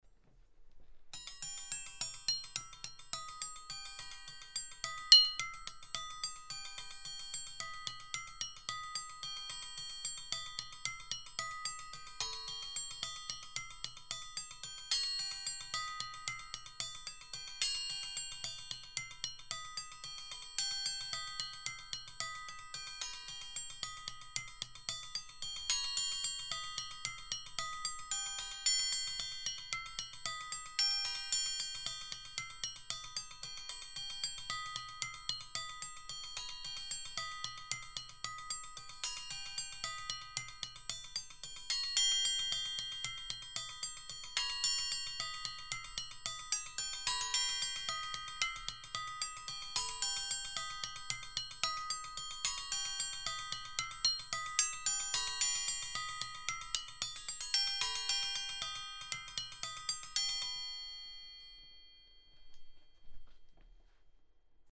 Paint Scraper Glockenspeil
Simple to execute and all came from finding that a paint scraper (cheaper the better - thinner metal) has a wonderfully resonant tone. The second one from the left has a much richer tone than the rest. The tuning is relative from low to high.
Paint Scraper Glock.mp3